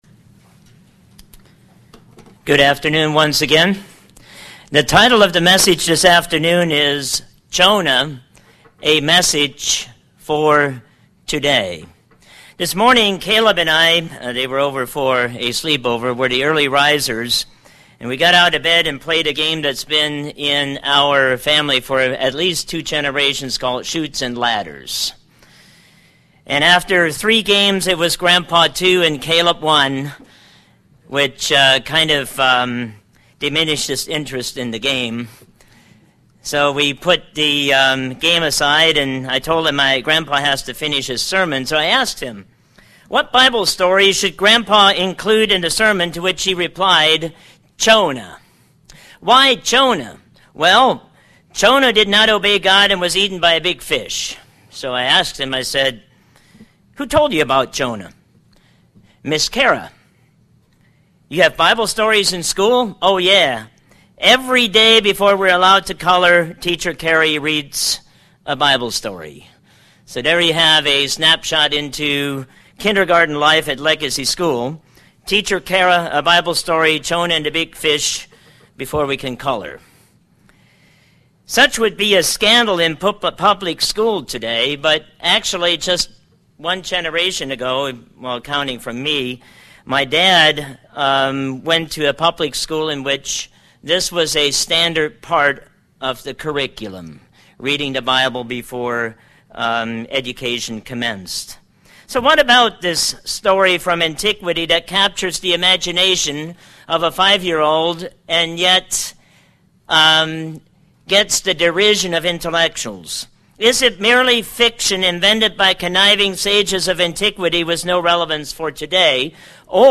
This sermon shows why the story of Jonah is relevant today by contrasting Acting on the Mind of Man vs. Acting on the Word of the Lord.